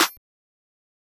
Clap (first take).wav